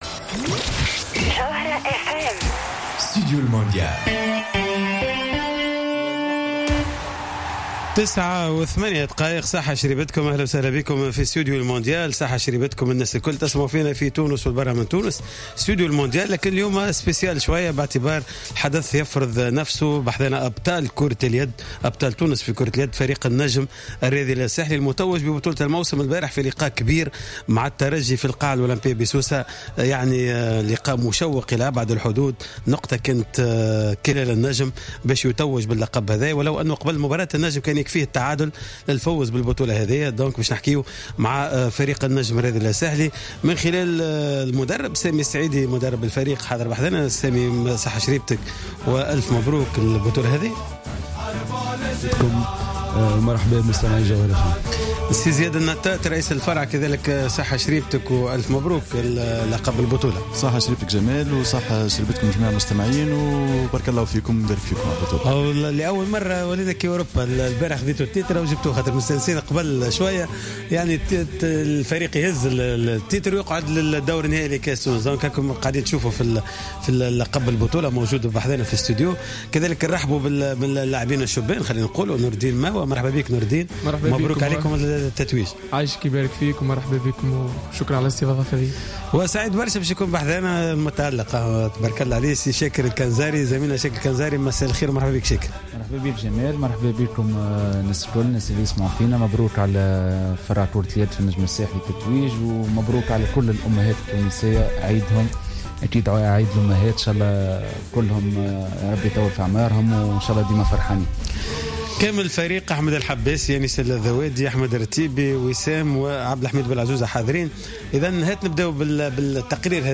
حل فريق النجم الساحلي المتوج ببطولة تونس لكرة اليد ضيفا في حصة خاصة من ستوديو...